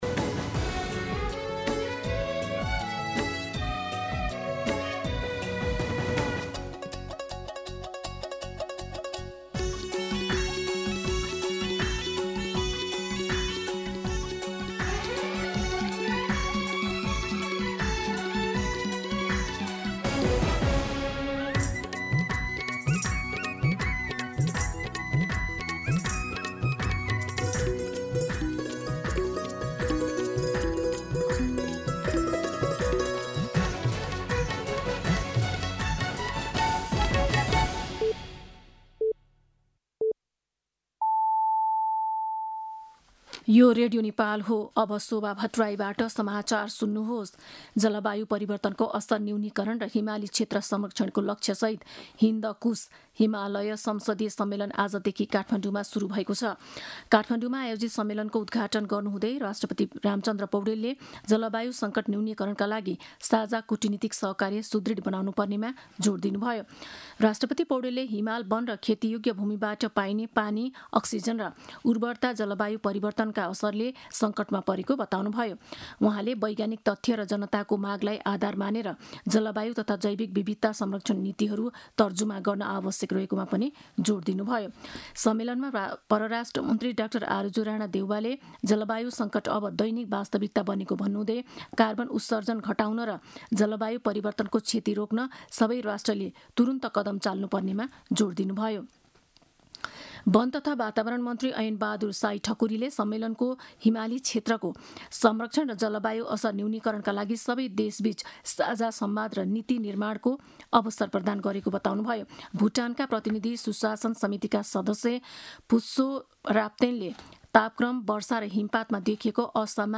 दिउँसो ४ बजेको नेपाली समाचार : २ भदौ , २०८२
4pm-News-02.mp3